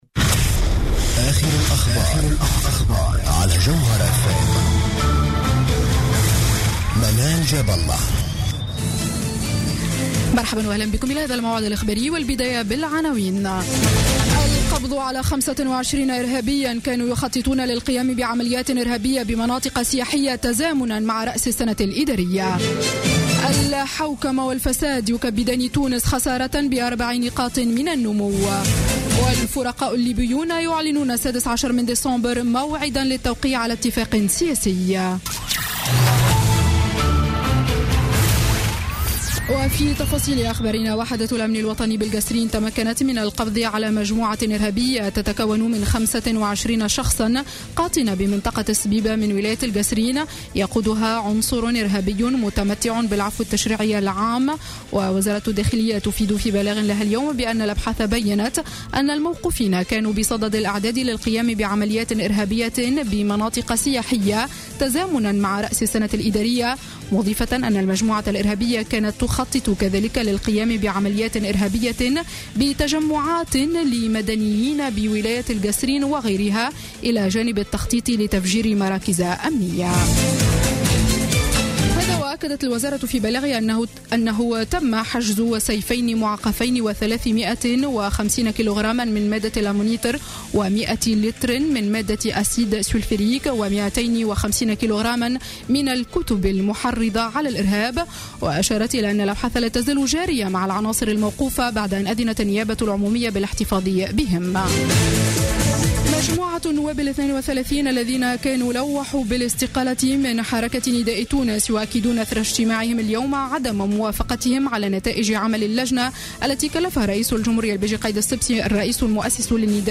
نشرة أخبار السابعة مساء ليوم الجمعة 11 ديسمبر 2015